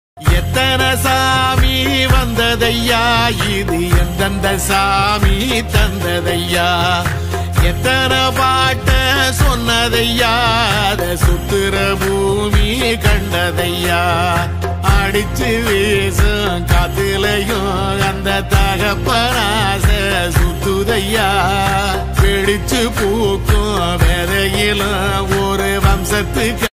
Tamil song